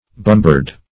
bumbard - definition of bumbard - synonyms, pronunciation, spelling from Free Dictionary
bumbard - definition of bumbard - synonyms, pronunciation, spelling from Free Dictionary Search Result for " bumbard" : The Collaborative International Dictionary of English v.0.48: Bumbard \Bum"bard\ See Bombard .